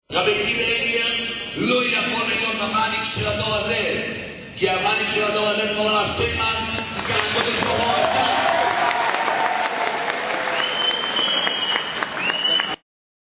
אך משהו משובש מאד באיכות ההקלטה שלך